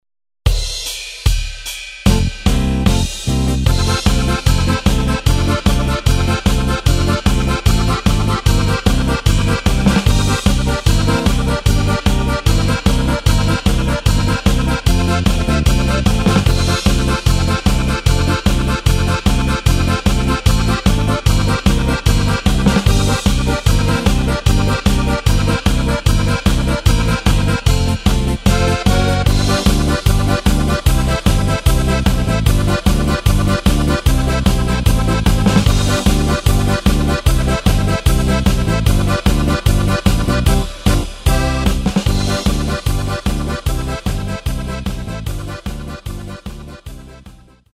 Tempo:         150.00
Tonart:            F
Solo Akkordeon Polka!
Playback mp3 Mit Drums